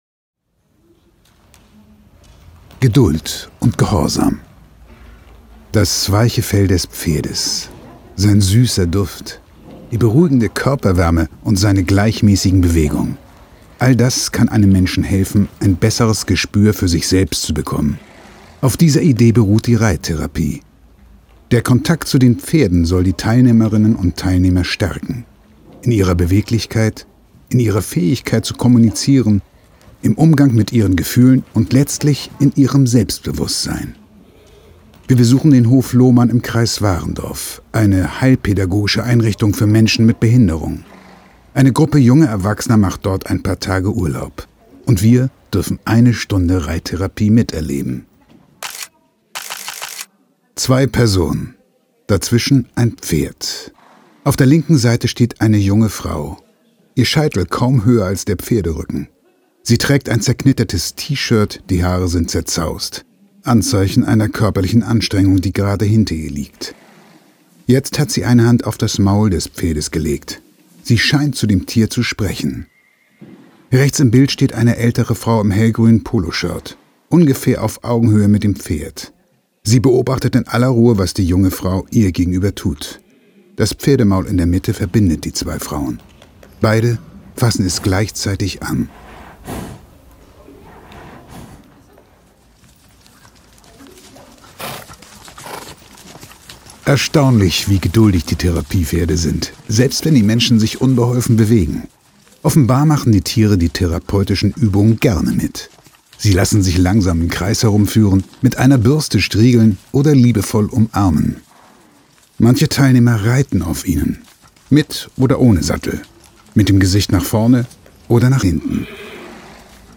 Unser Hörbuch genießen Sie am besten mit Kopfhörern .
pferde2020_hoerbuch_taste_2__geduldundgehorsam__master.mp3